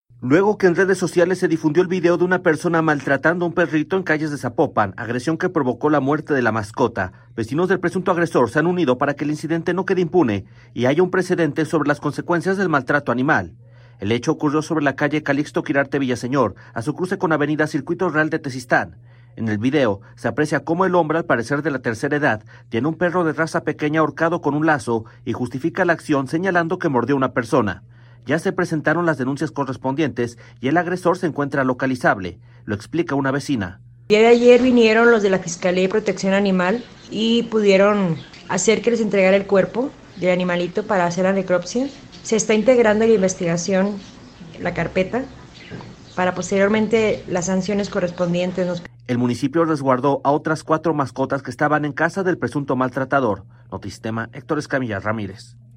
Ya se presentaron las denuncias correspondientes y el agresor se encuentra localizable. Lo explica una vecina: